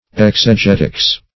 Exegetics \Ex`e*get"ics\, n. The science of interpretation or exegesis.